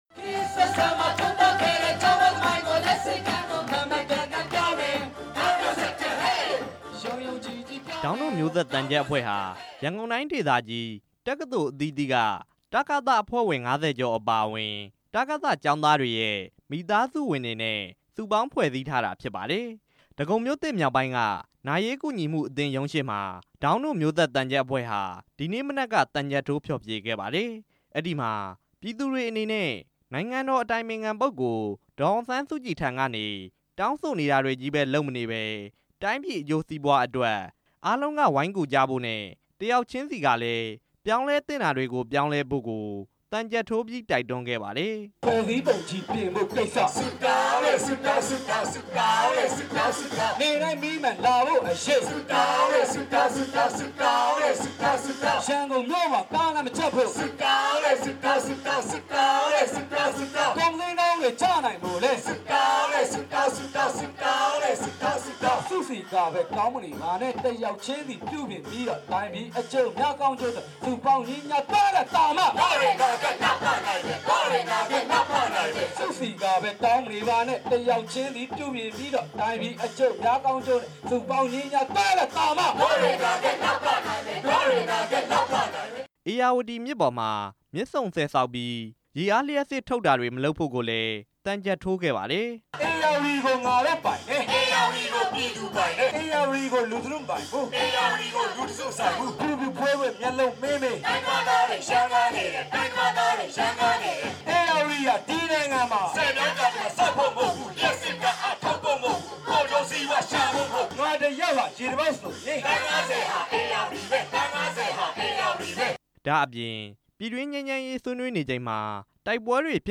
ရန်ကုန်မြို့ အခြေစိုက် တက္ကသိုလ်ကျောင်းသားများ သမဂ္ဂအဖွဲ့ဝင်တွေရဲ့ "ဒေါင်းတို့မျိုးဆက်" သံချပ်အဖွဲ့ဟာ ဒဂုံမြို့သစ် (မြောက်ပိုင်း)က နာရေးကူညီမှုအသင်း (ရန်ကုန်) ရုံးရှေ့မှာ ဒီနေ့မနက်က သံချပ်ထိုး ဖျော်ဖြေခဲ့ပါတယ်။